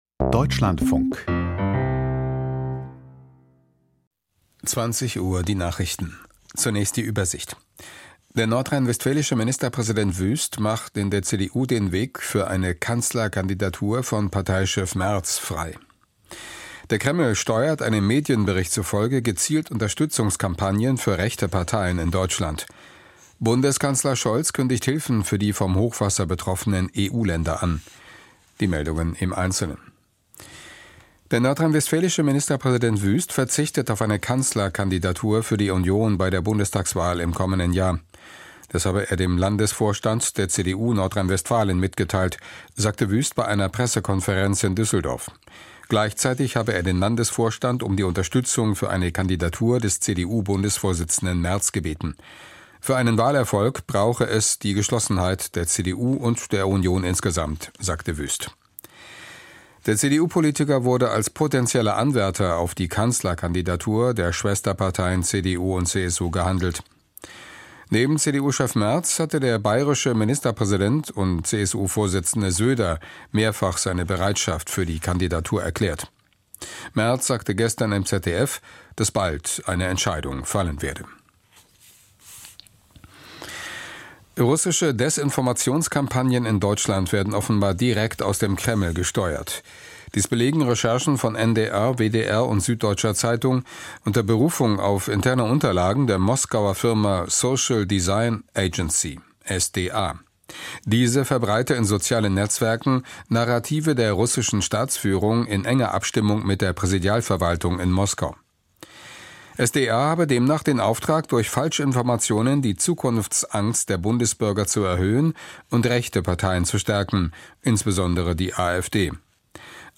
Neue berufliche Perspektiven für junge Erwachsene: Interview